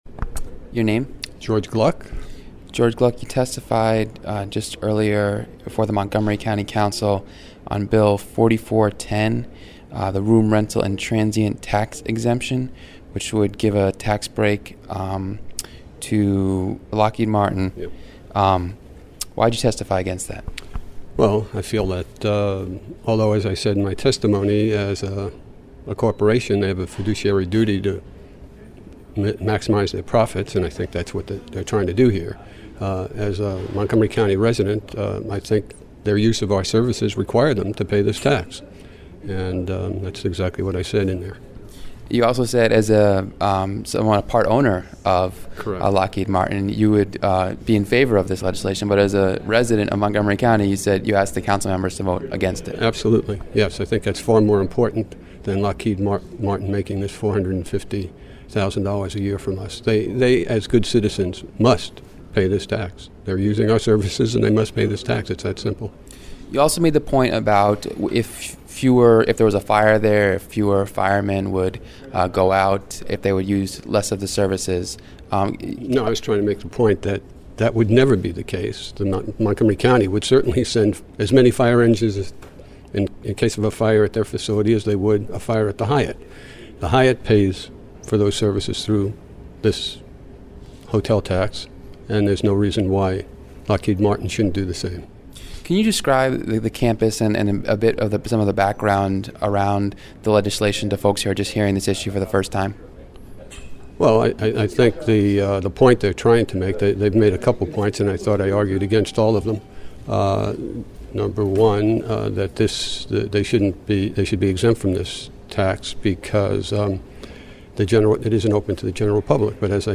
Delegate Ana Sol Gutierrez after testifying at the Montgomery County Council against a tax break for Lockheed Martin. (9/21/10)
Lockheed-Martin-Hearing-9-21-10.mp3